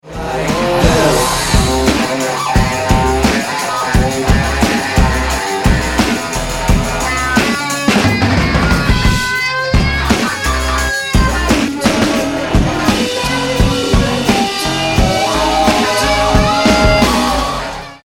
Clip 4 (2:43) – Solo section